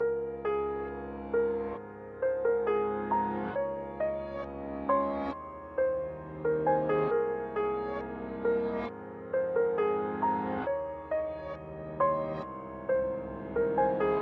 描述：钢琴... 反转钢琴...
Tag: 135 bpm Chill Out Loops Piano Loops 2.39 MB wav Key : A